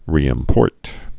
(rēĭm-pôrt, rē-ĭmpôrt)